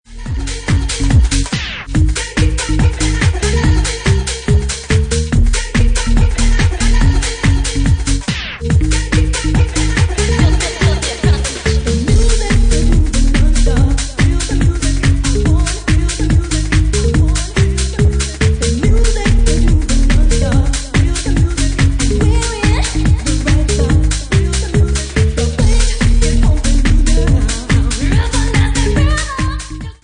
Bassline House at 146 bpm